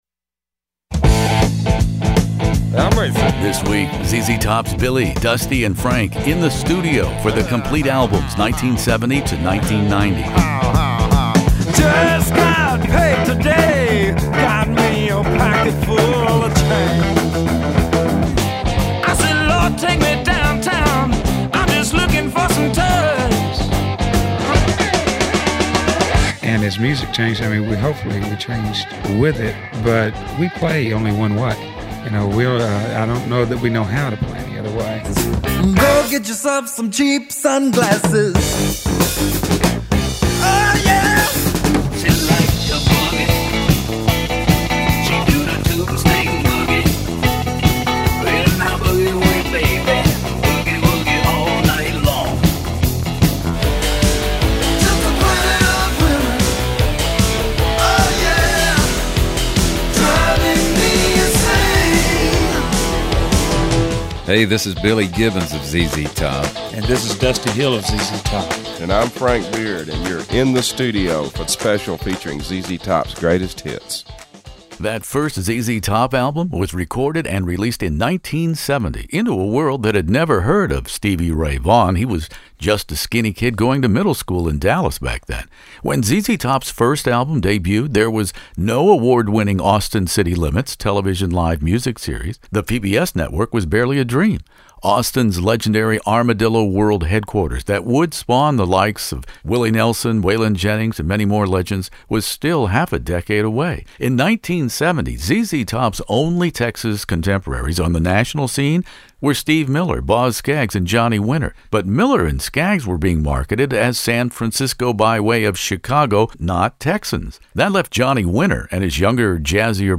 ZZ Top Fandango 45th anniversary interview with Billy Gibbons,Dusty Hill, Frank Beard In the Studio